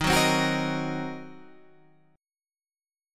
D# Minor 6th